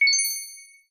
Coin.ogg